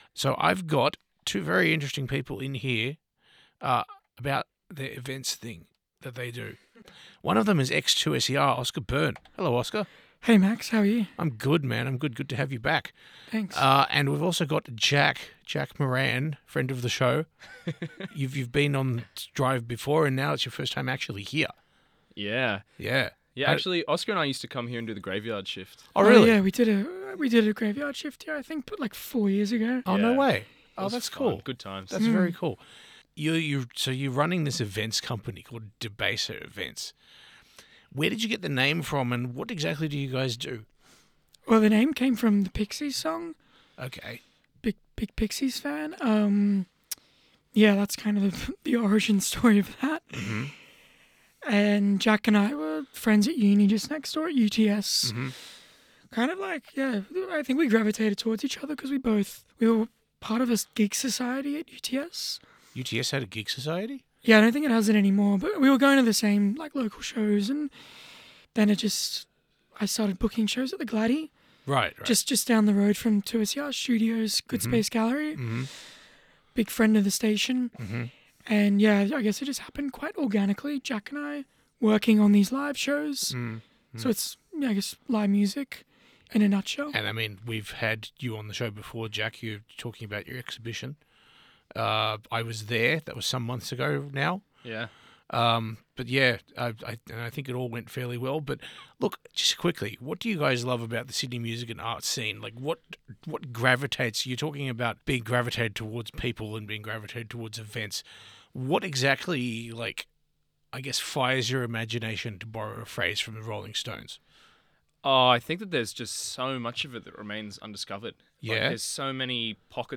Debaser-Interview-Edited.mp3